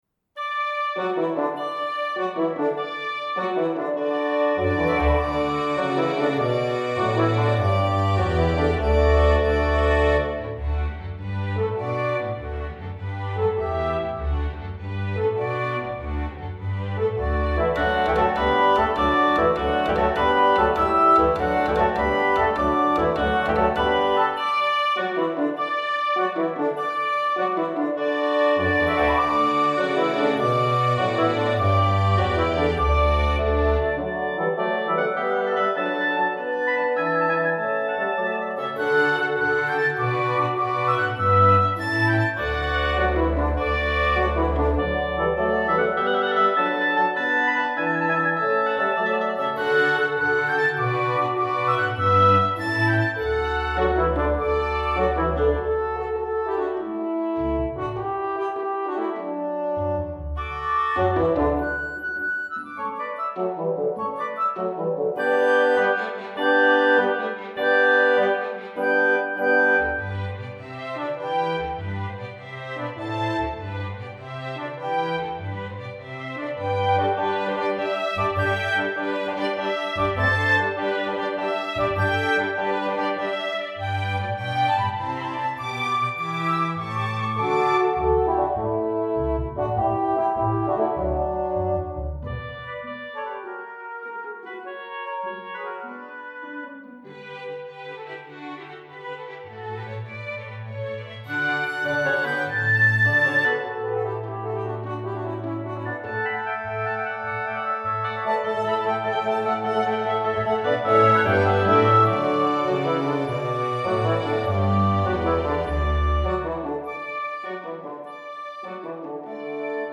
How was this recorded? Digital Orchestration